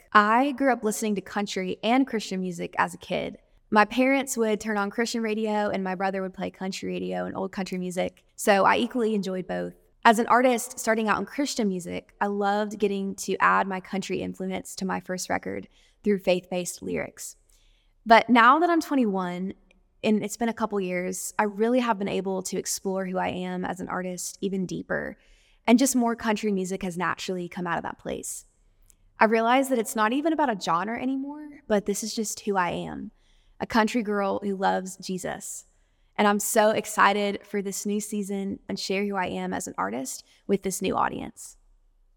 Anne Wilson explains how she has been drawn to Country Music.
Anne-Wilson-drawn-to-Country-Music.mp3